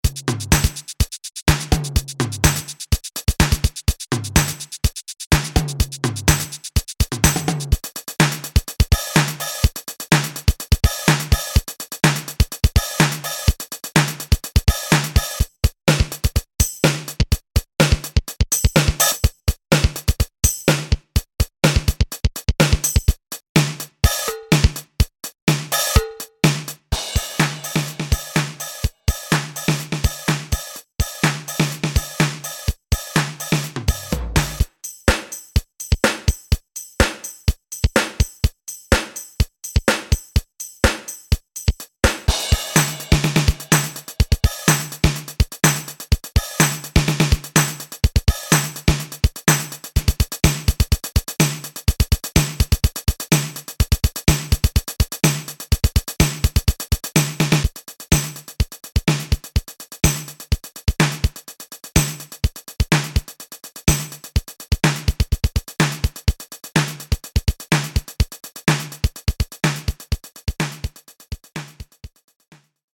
80年代的经典之声瞬间降临你的DAW。RX5鼓机在80年代风靡一时，曾被New Order、Pet Shop Boys和Prince等众多音乐人使用。
•10个循环乐段